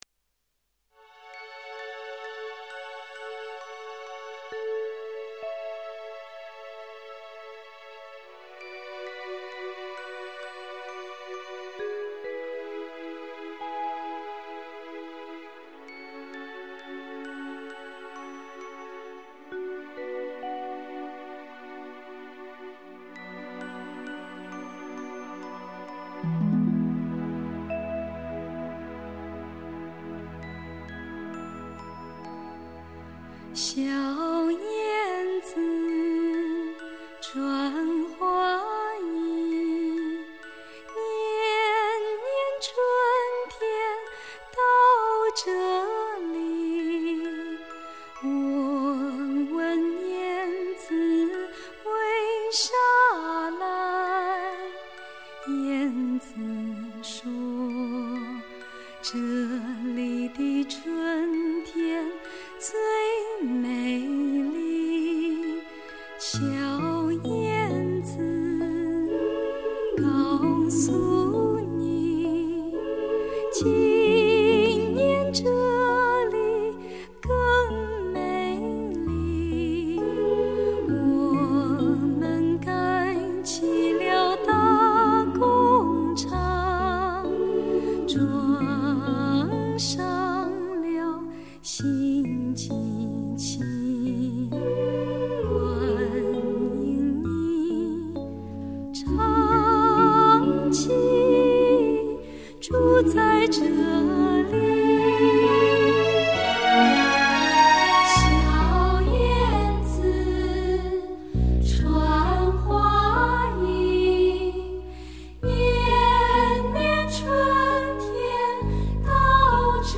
歌曲清新流畅、自然朴实，毫无斧凿之痕。